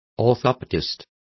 Complete with pronunciation of the translation of orthopaedists.